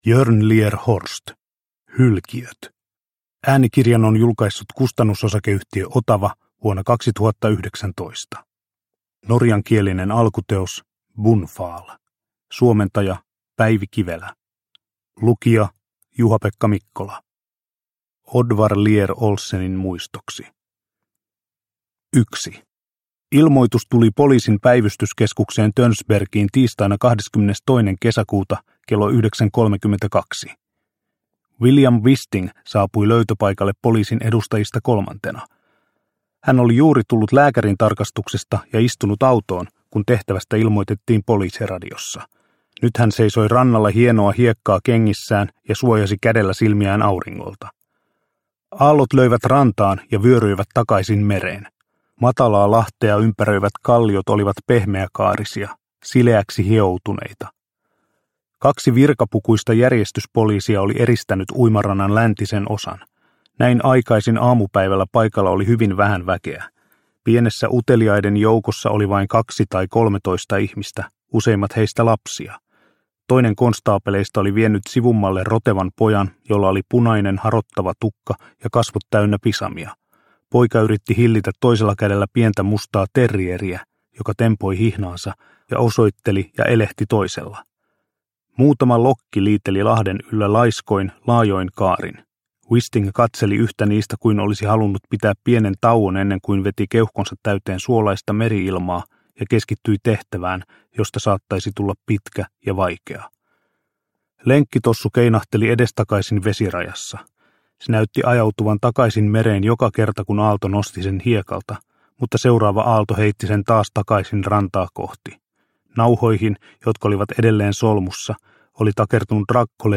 Hylkiöt – Ljudbok – Laddas ner